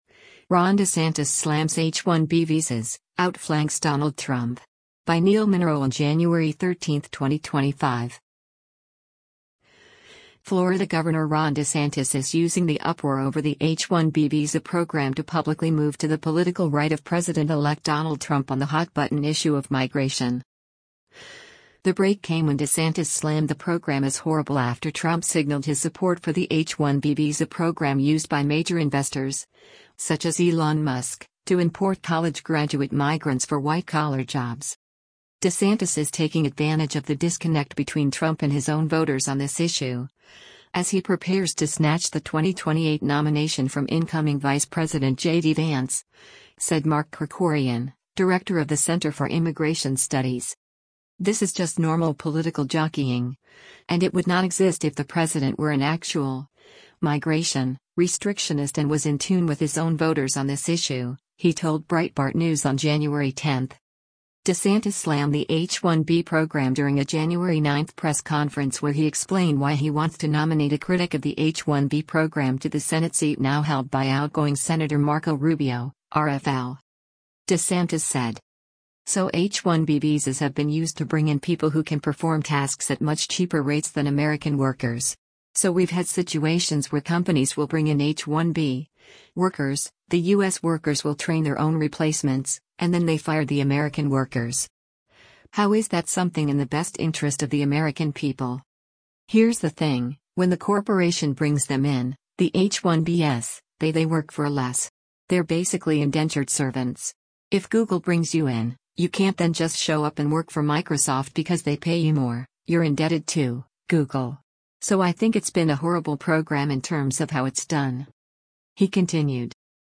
DeSantis slammed the H-1B program during a January 9 press conference where he explained why he wants to nominate a critic of the H-1B program to the Senate seat now held by outgoing Sen. Marco Rubio (R-FL).